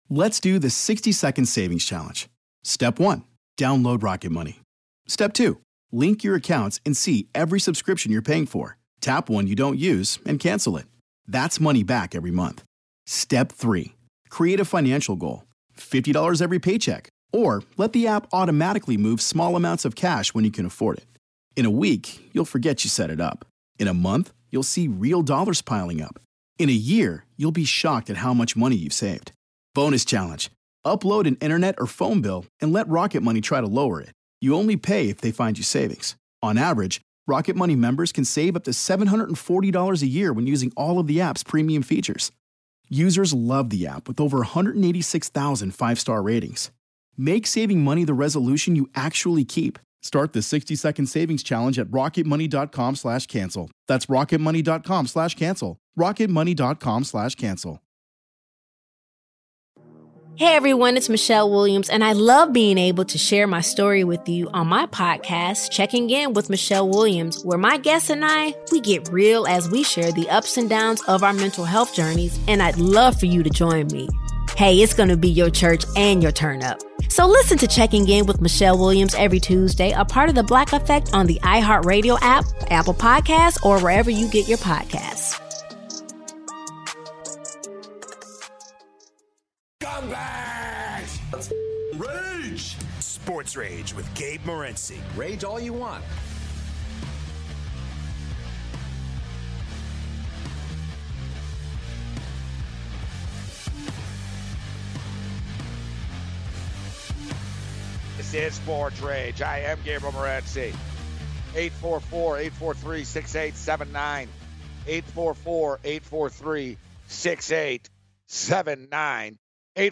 New MLB Proposal, Callers Vent Their Rage